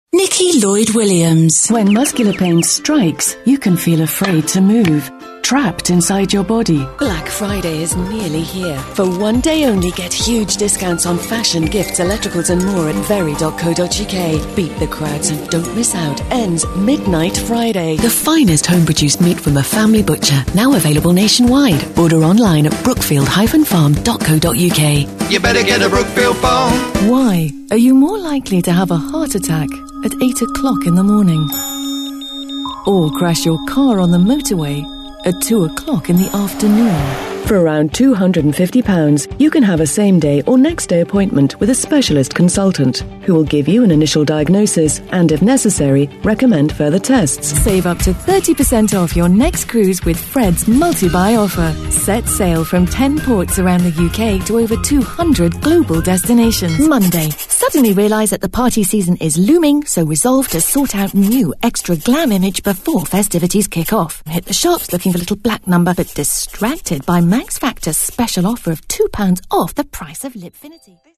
Female / 40s, 50s / English / RP, Southern
Showreel